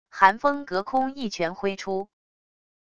韩风隔空一拳挥出wav音频